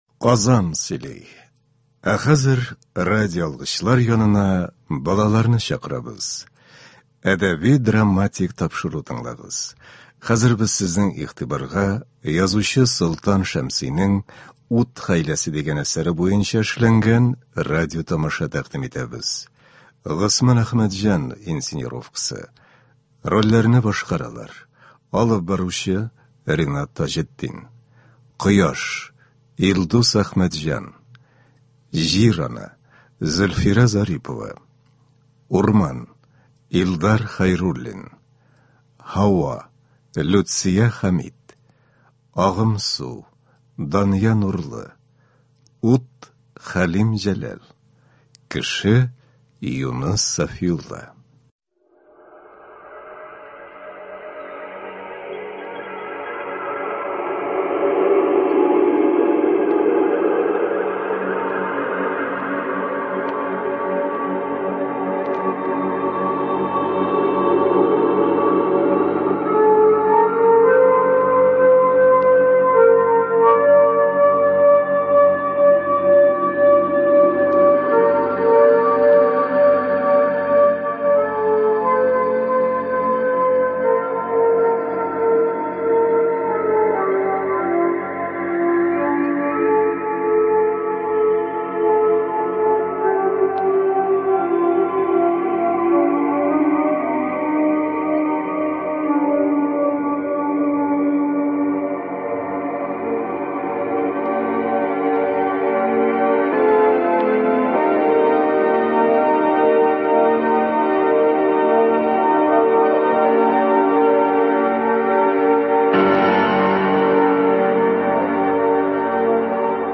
“Ут хәйләсе”. Радиотамаша.